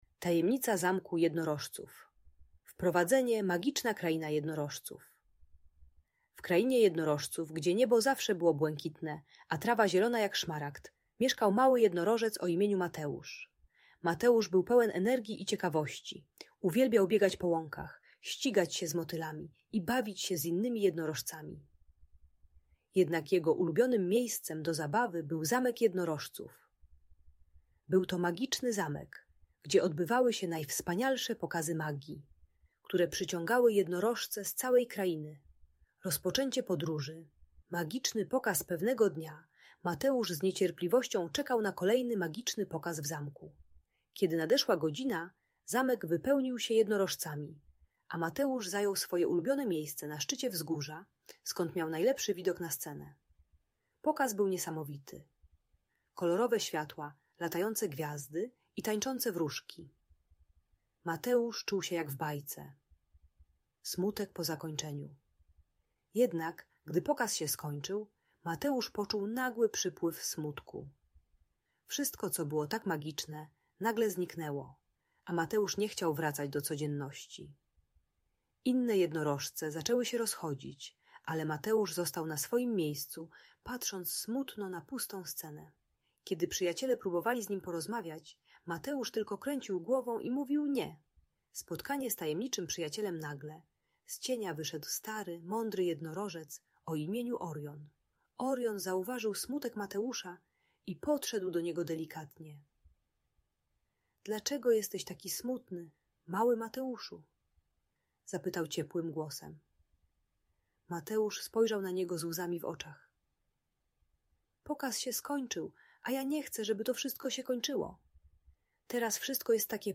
Tajemnica Zamku Jednorożców - Magiczna story o Mateuszu - Audiobajka dla dzieci